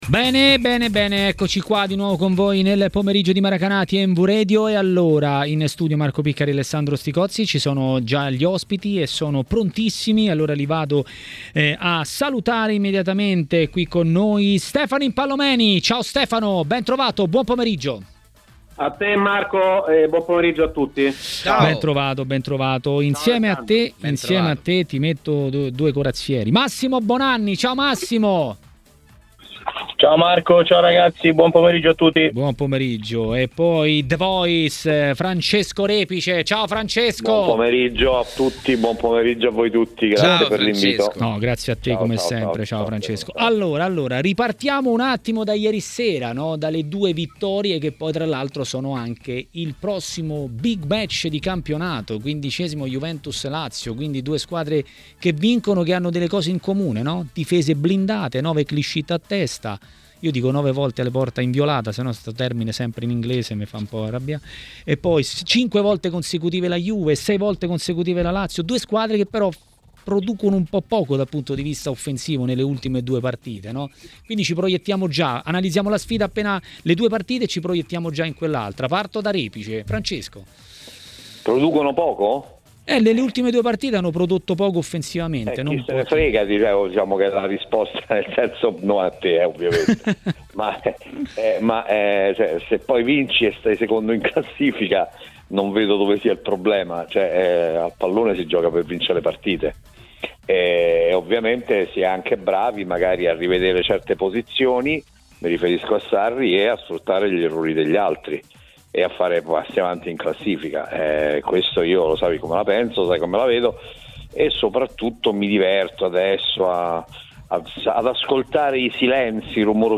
Francesco Repice, noto radiocronista, ha parlato a Maracanà, nel pomeriggio di TMW Radio, della Serie A.